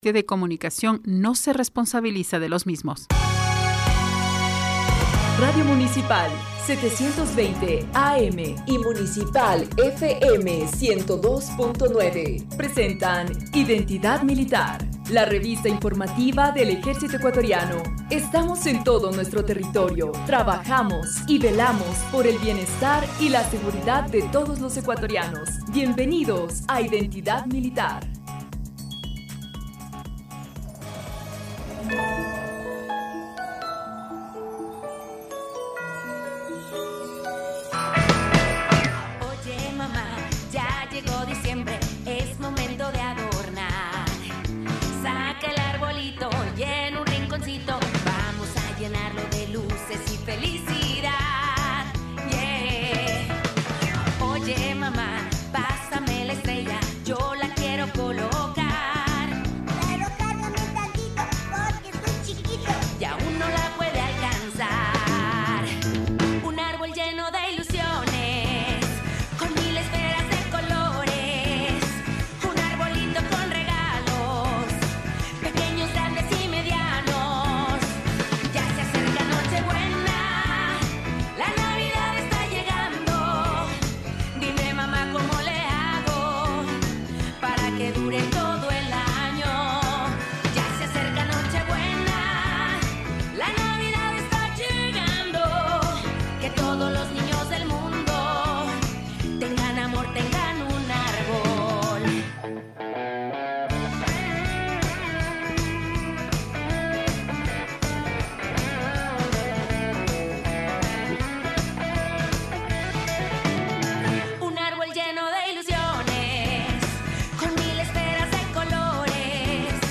El Crnl. Iván Ramírez, director del Instituto Geográfico Militar (IGM), participó en una entrevista en Radio Municipal en el programa “Identidad Militar”, donde informó sobre la elaboración de los Mapas Oficiales del Ecuador 2024.
Entrevista_Mapas_2024_Ecuador_Radio_Municipal.mp3